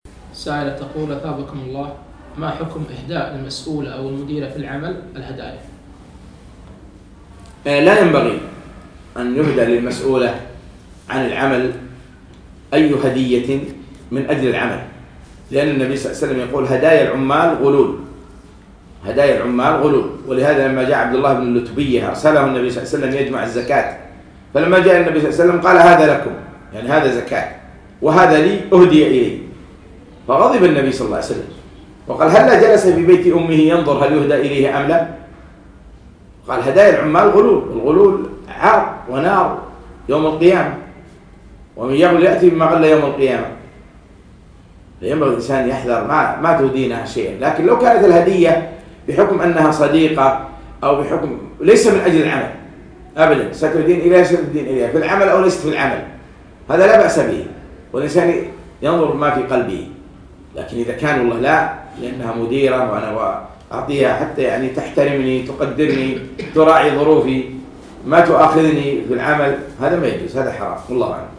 مقتطف من محاضرة فوائد وعبر من سورة الكهف المقامة في مركز إلهام البوشي بتاريخ 3 4 2017